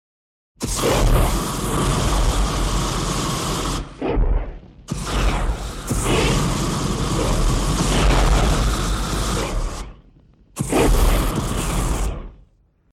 На этой странице собраны реалистичные звуки огнемета — от гула воспламенения до рева пламени.
3. Яркие вспышки огнемёта